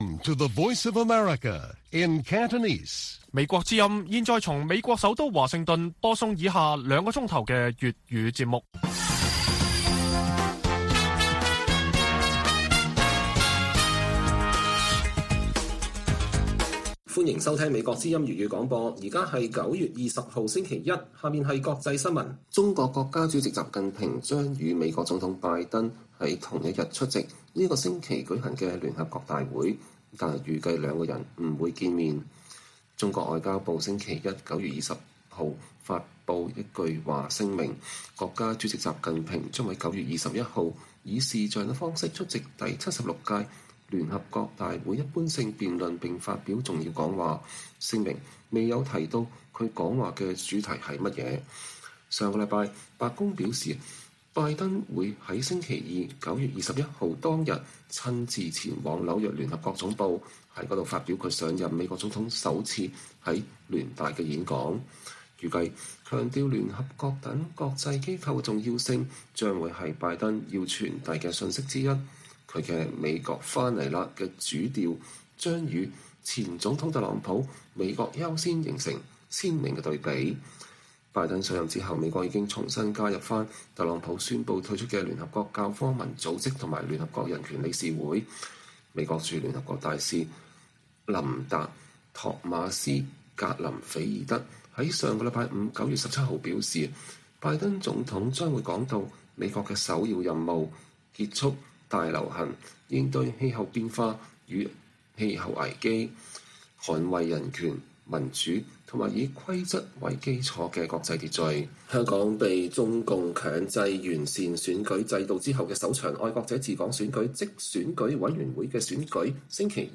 粵語新聞 晚上9-10點 : 香港選委會選舉只4380人投票 建制派不滿花逾14小時龜速點票